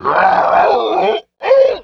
Werewolf Death Sound
horror